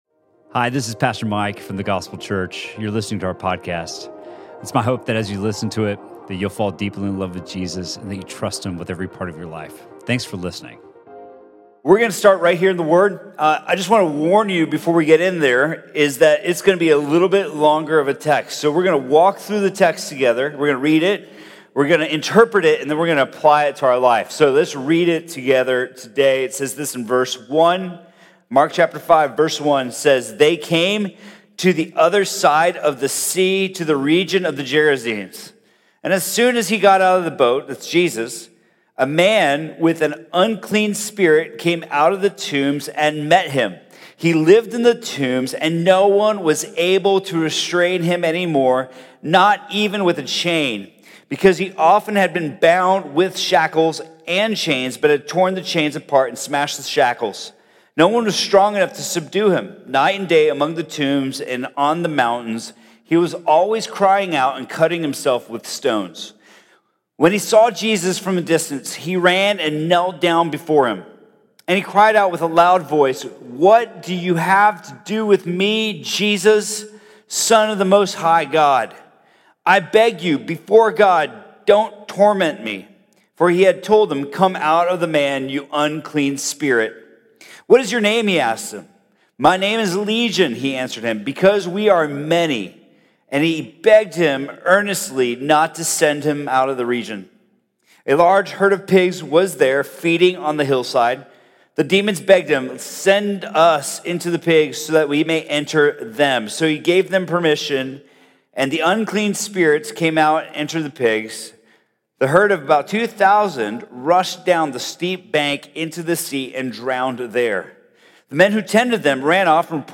Sermon from The Gospel Church on May 5th, 2019.